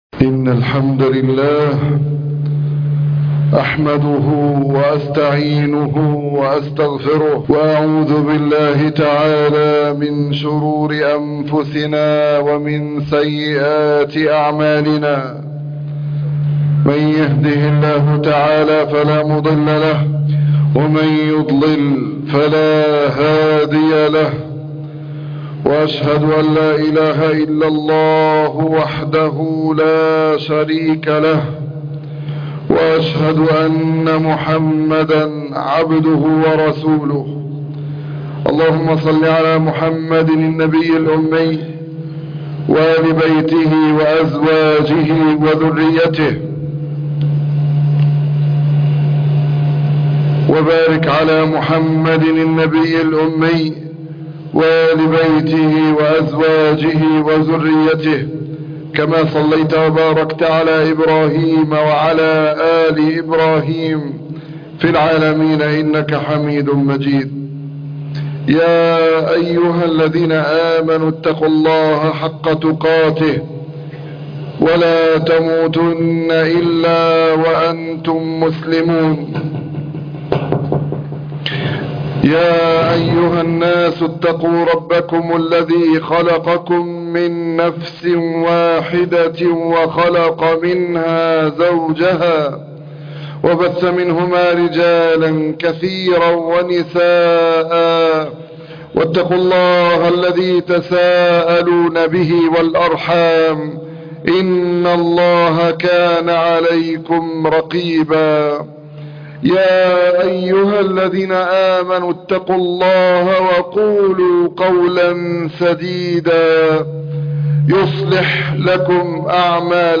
تاسوعاء خطبة الجمعة